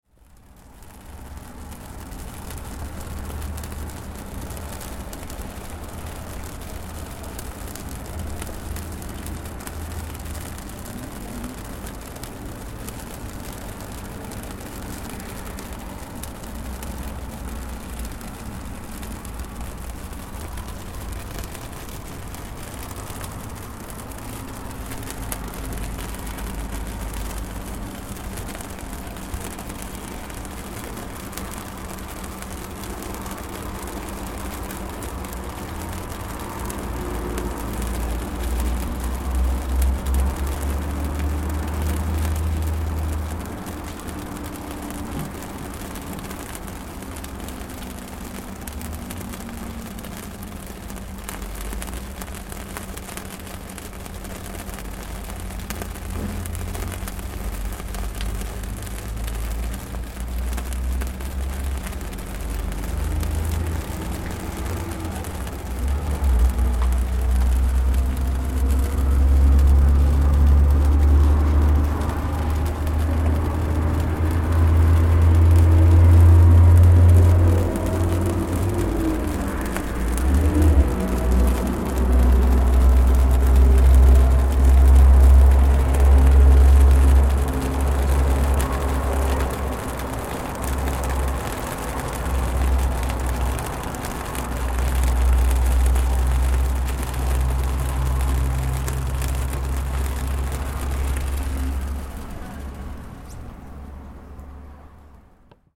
Church candles fizzing in the water
Ružica Church (outside), Belgrade, Serbia: Candles in standing water